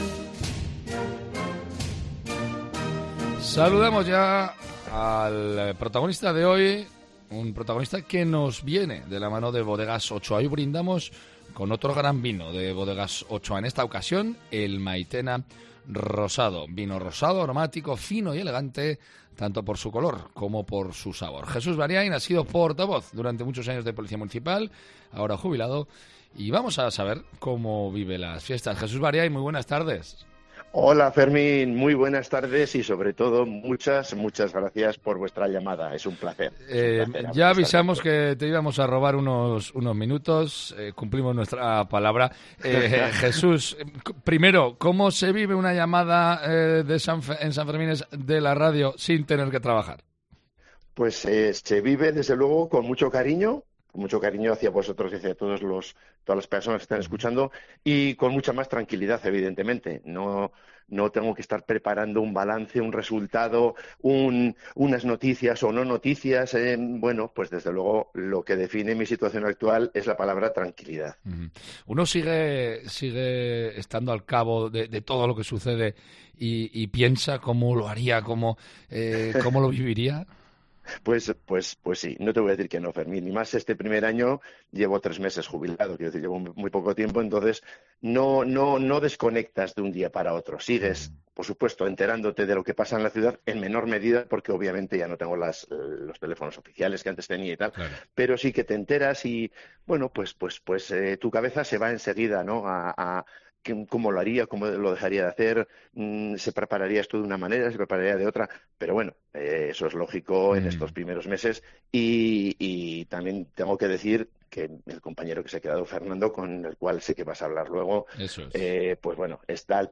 la entrevista del día con Bodegas Ochoa